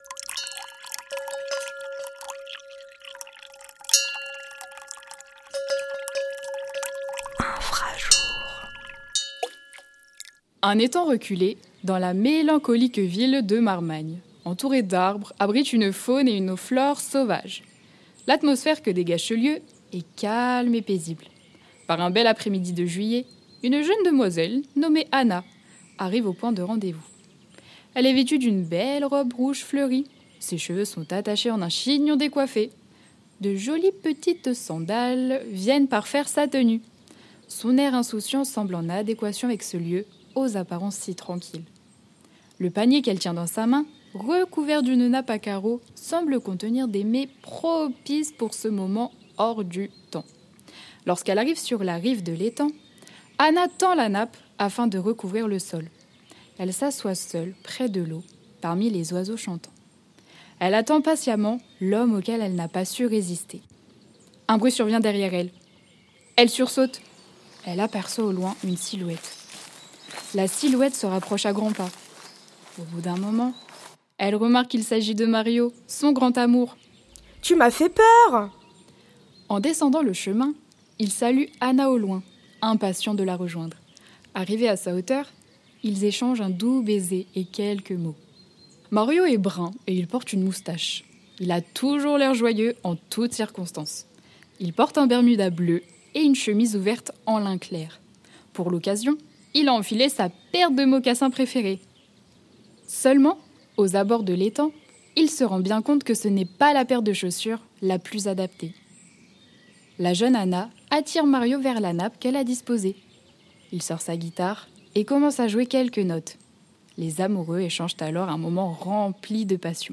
Musiques et ccompagnements sonores